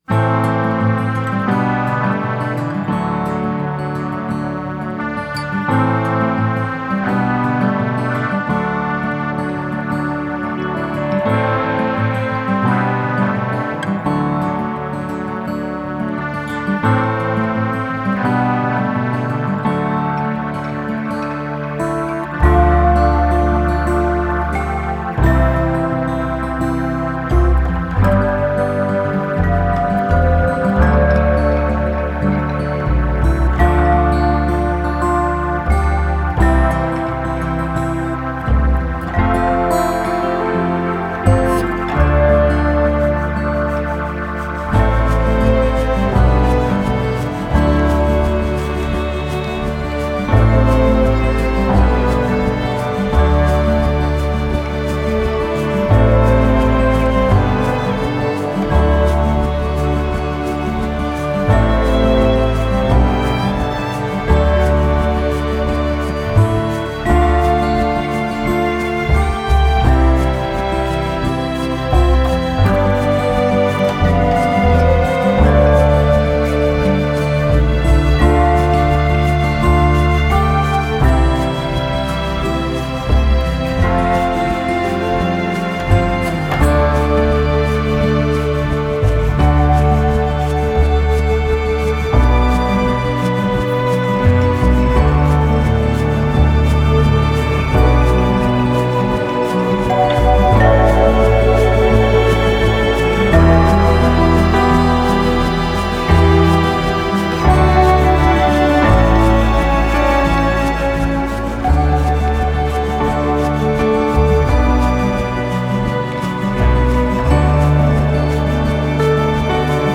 Genre: Indie Pop-Rock / Indie-Folk /